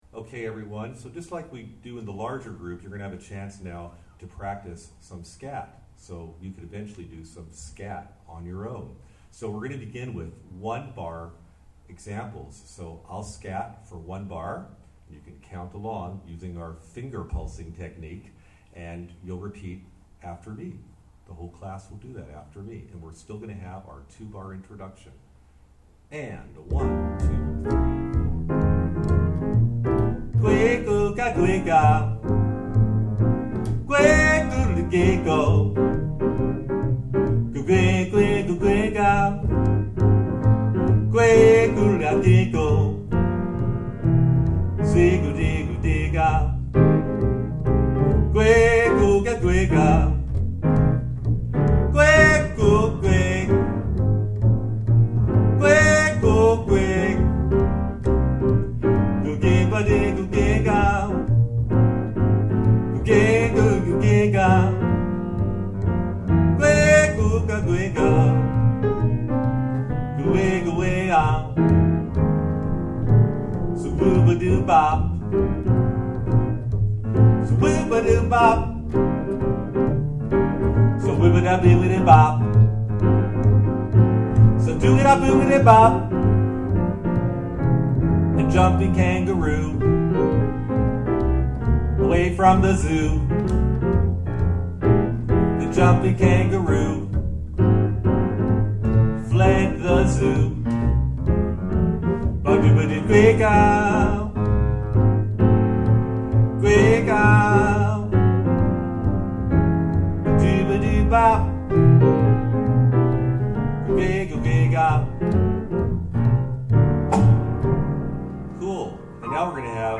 creating-your-own-blues-track-3.mp3